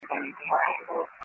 EVP's